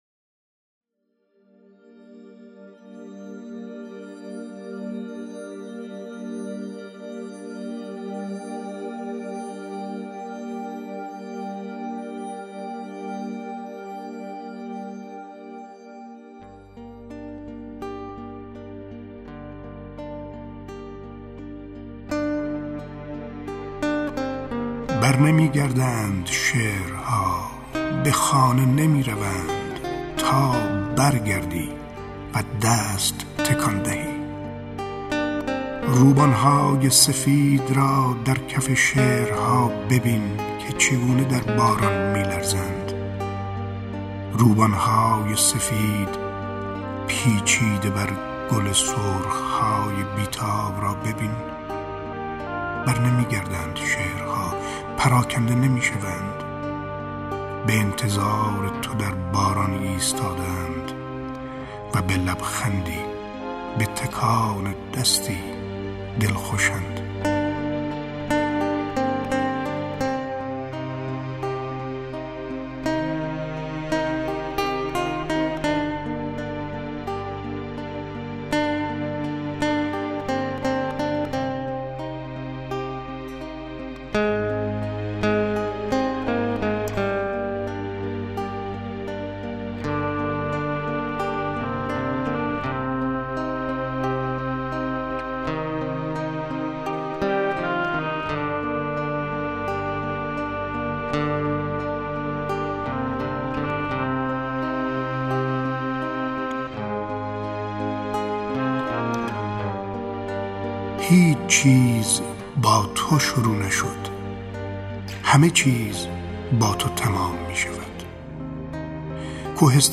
دانلود دکلمه برنمیگردند شعرها با صدای شمس لنگرودی
گوینده :   [شمس لنگرودی]